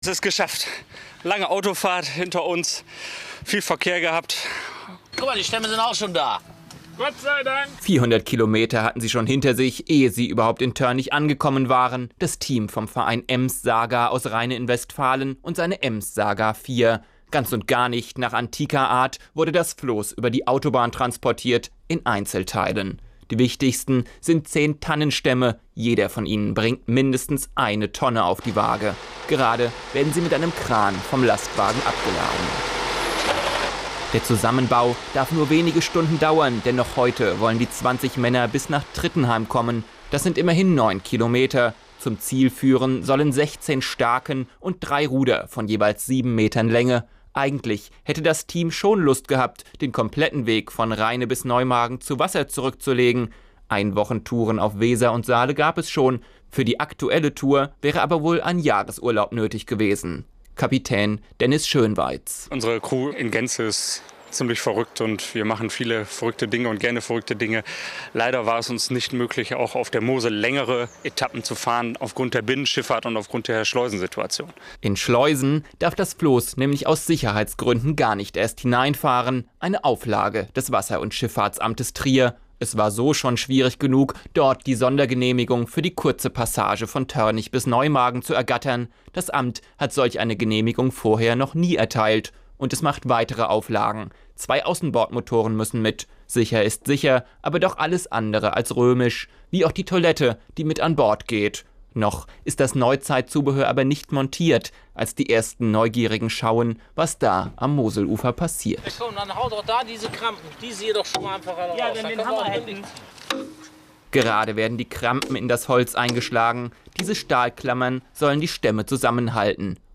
Mitschnitt des Beitrags „Floßbau auf der Mosel“ aus der Sendung auf SWR4 (5 MB)